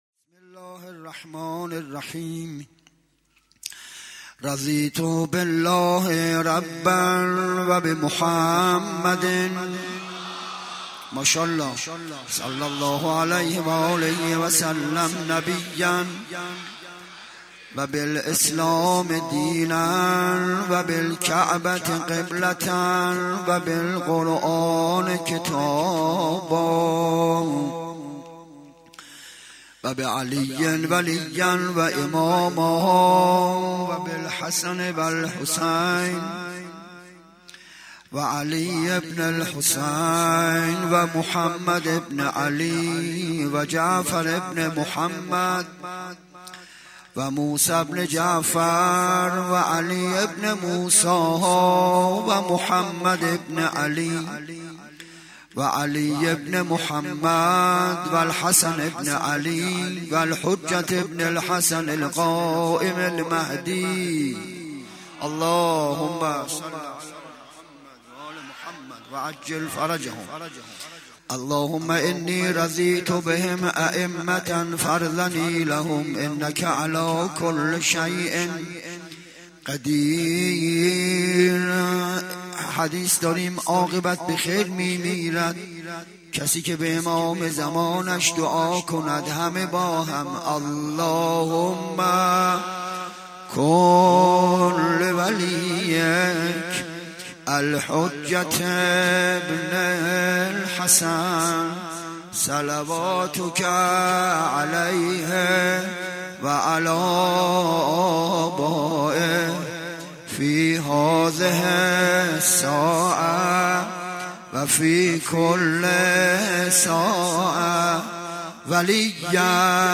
پانزدهم محرم 96 - حسینیه کربلایی های یزد - سخنرانی